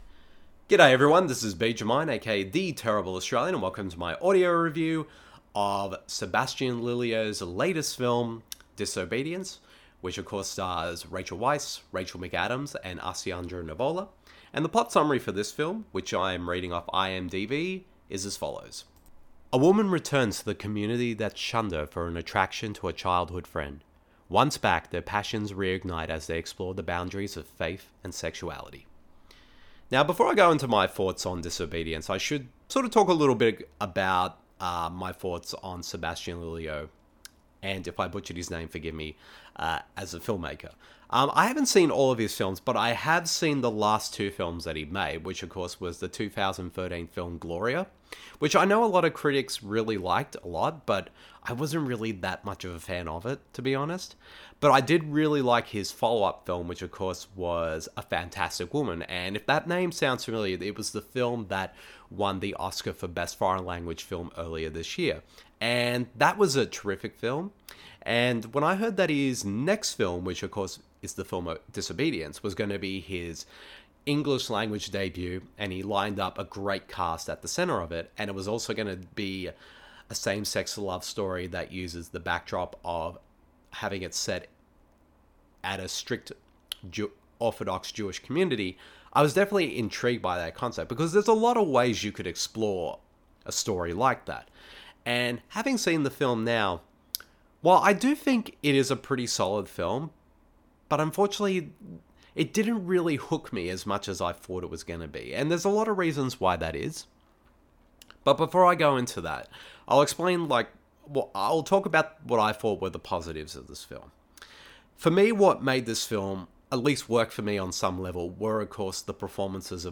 Now Lelio is back with his latest film DISOBEDIENCE starring Rachel Weisz and Rachel McAdams, an exploration on sexuality and faith that also marks his English language film debut. The following review of the film is in an audio format.